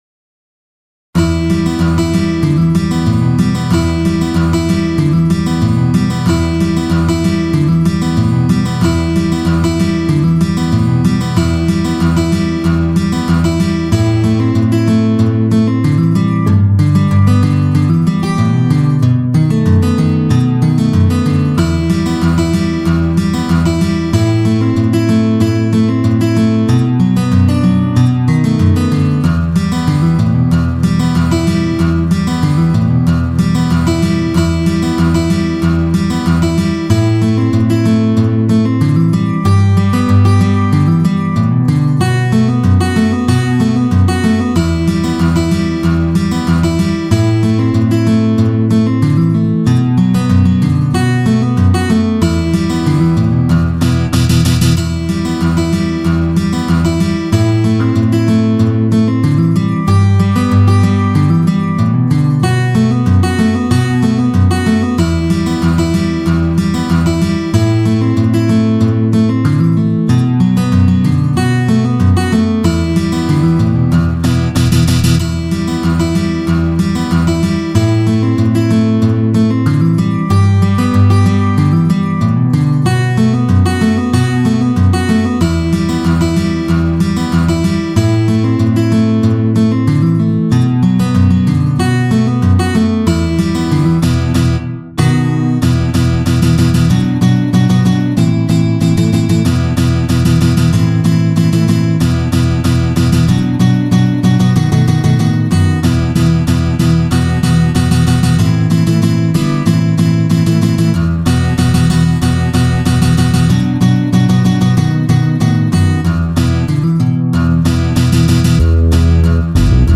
T Guitar